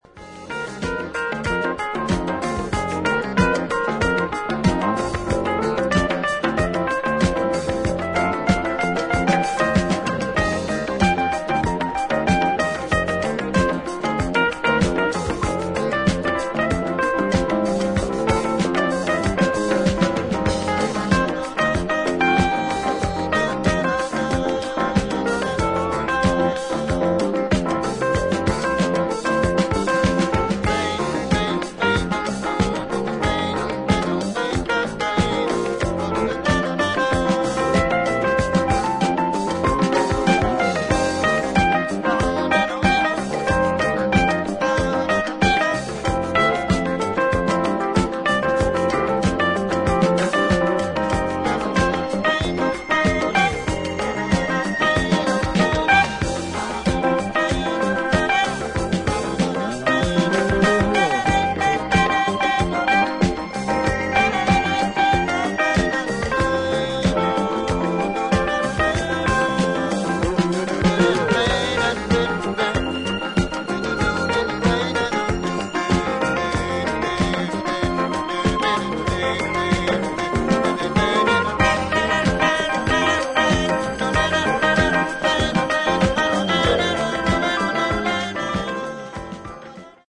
各プレイヤーのスキルが際立つ、ソウルフルでファンキーな名演を披露しています。